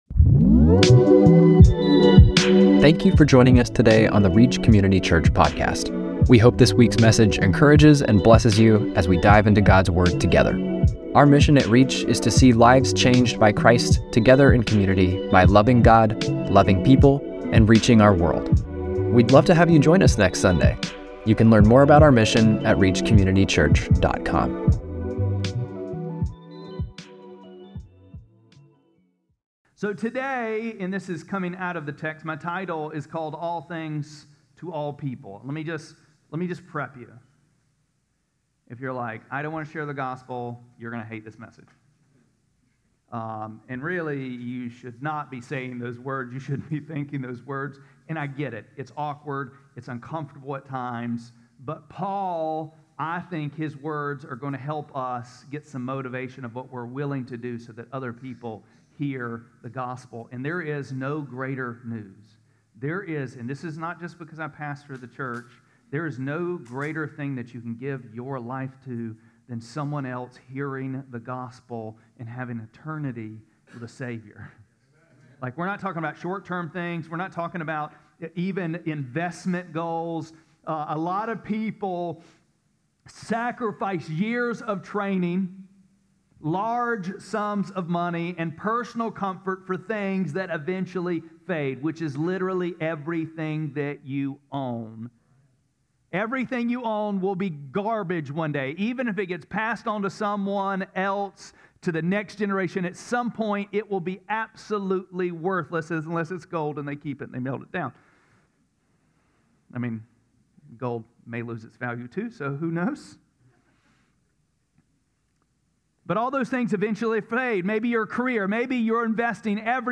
8-24-25-Sermon.mp3